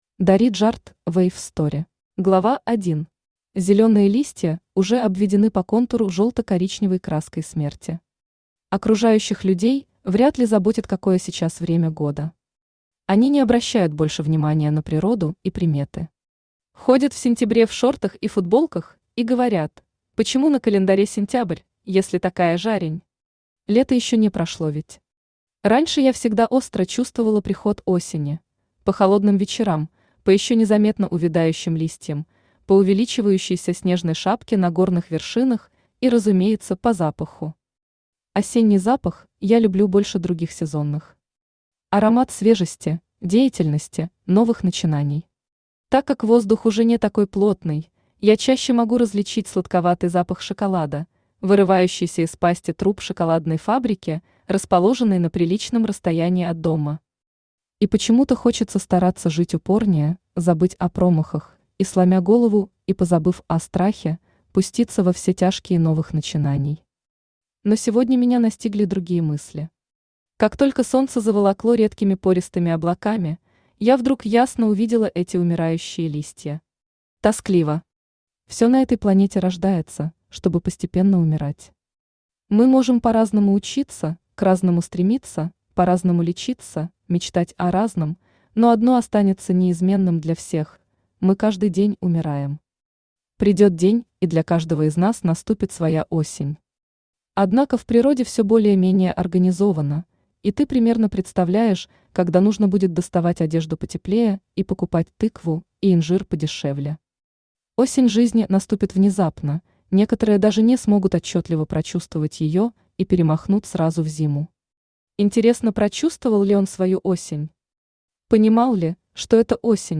Аудиокнига Вэйв Стори | Библиотека аудиокниг
Aудиокнига Вэйв Стори Автор Дари Джарт Читает аудиокнигу Авточтец ЛитРес.